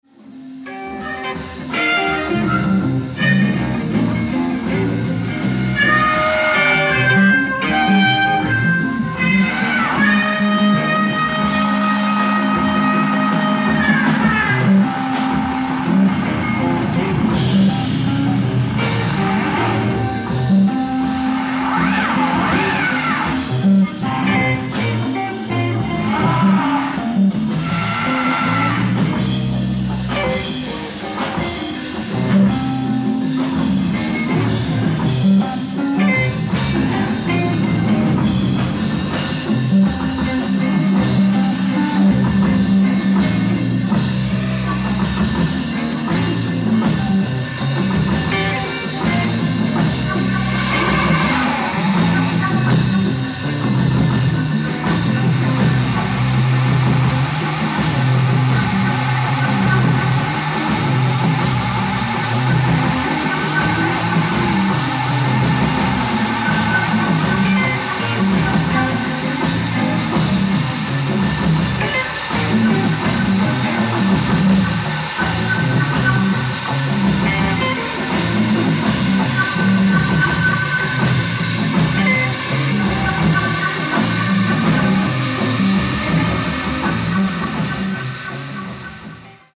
Live At Shinjuku Kohseinenkin Hall, Tokyo, Japan 02/07/1975
INCREDIBLE AUDIENCE RECORDING